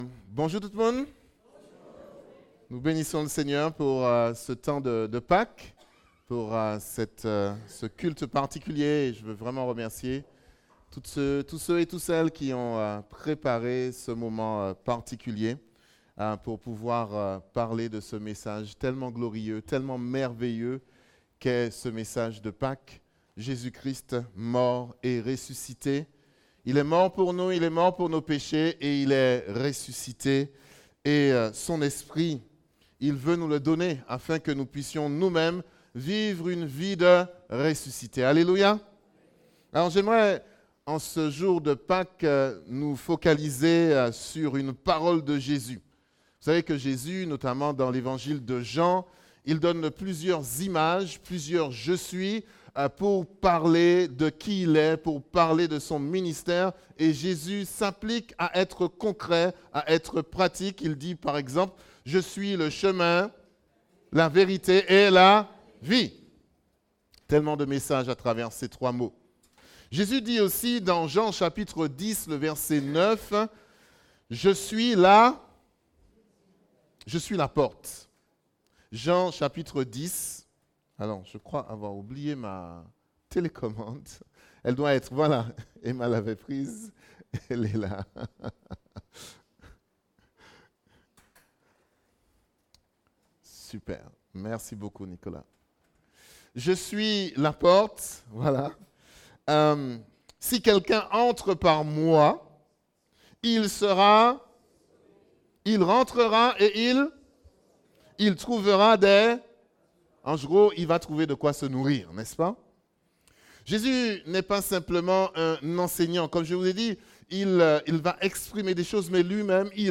la porte de ta liberté Prédicateur